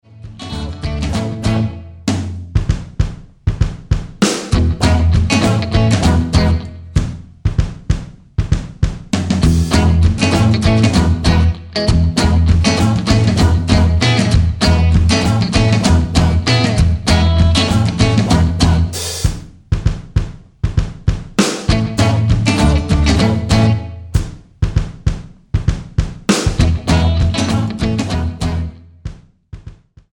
Tonart:E mit Chor
Die besten Playbacks Instrumentals und Karaoke Versionen .